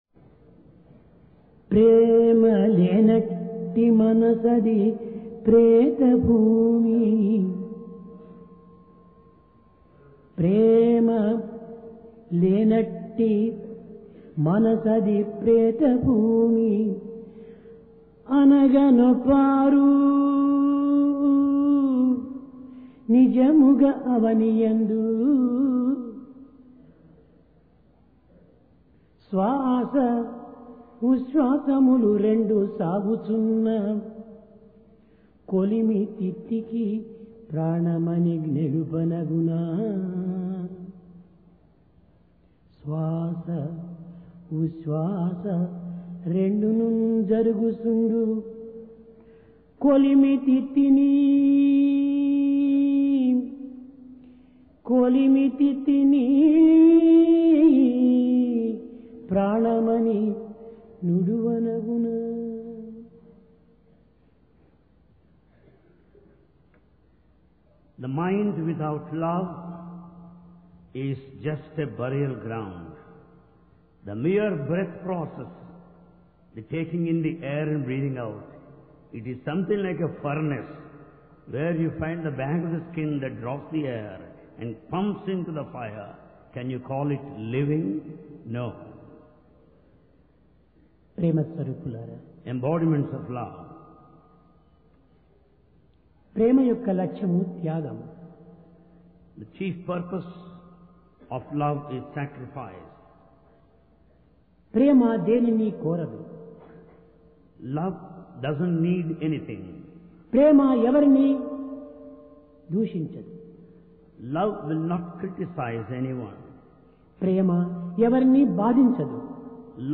Sai Darshan Home Date: 20 Jun 1996 Occasion: Divine Discourse Place: Prashanti Nilayam Pure And Divine Love The mind without Love is a burial ground, breathing is like a furnace.